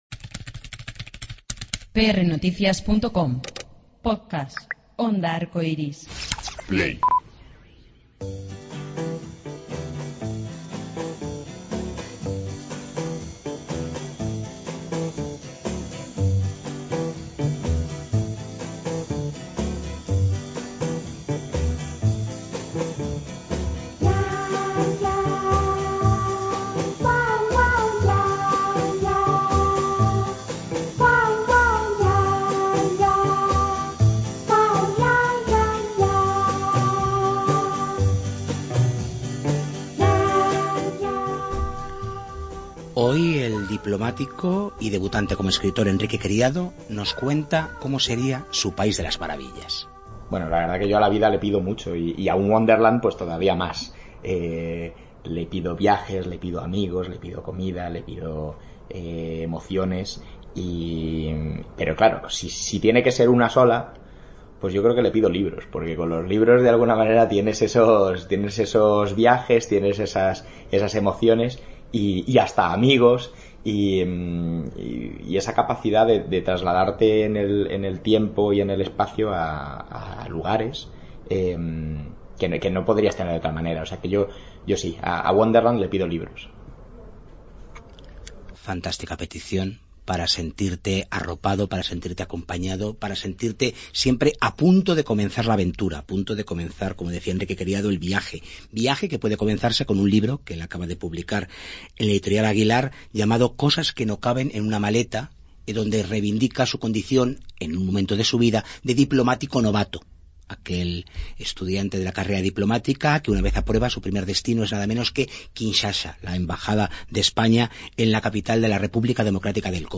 Consuelo Trujillo recibe a los micrófonos de Destino: Wonderland en Nave 73, sala en la que ofrecerá próximamente Criatura, uno de sus proyectos más queridos, un montaje que ha ido reelaborando, matizando y ampliand